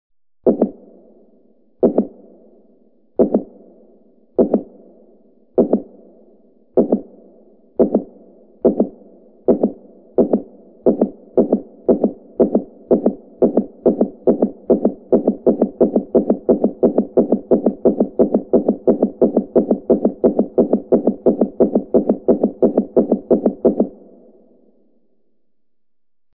دانلود صدای ضربان قلب نامنظم و آرام از ساعد نیوز با لینک مستقیم و کیفیت بالا
جلوه های صوتی